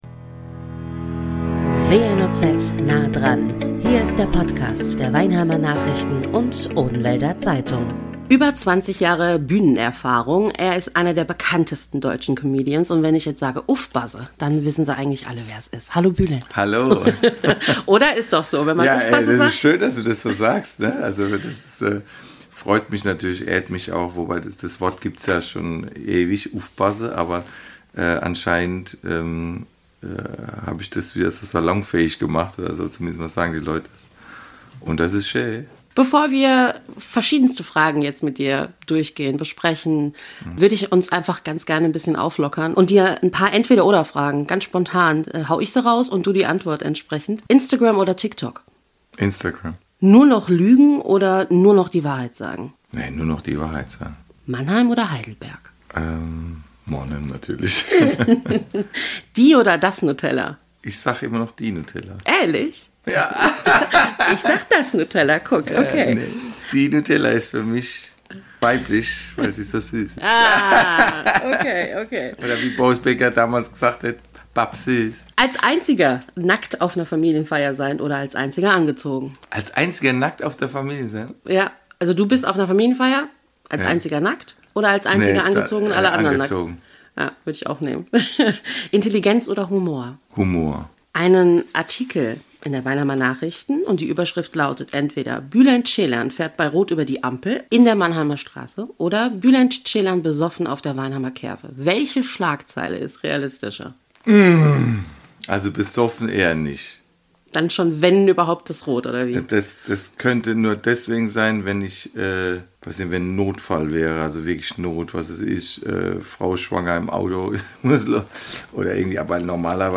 Bülent Ceylan im exklusiven Interview ~ WNOZ Nah dran Podcast
Studiogast: Comedian Bülent Ceylan.